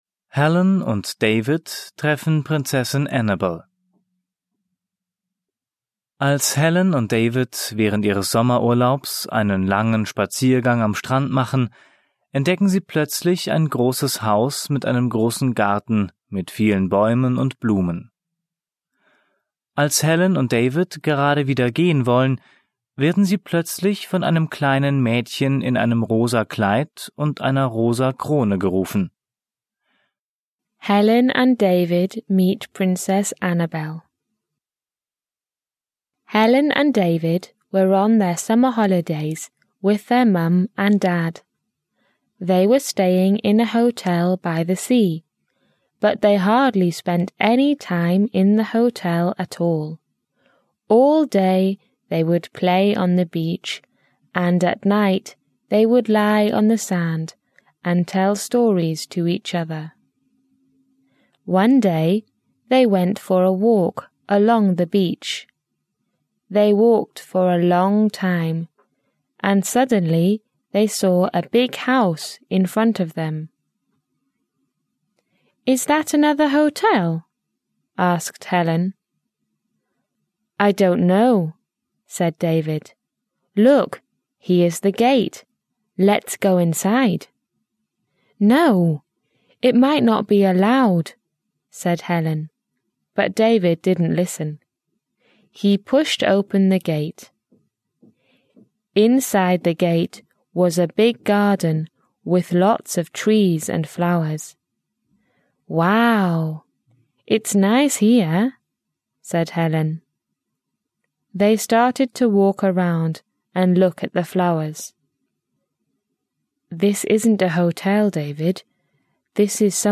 Vor jeder englischen Geschichte ist eine kurze Einführung auf Deutsch gegeben, die den Inhalt der Geschichte kurz zusammenfasst. Alle Geschichten sind von Muttersprachlern gesprochen.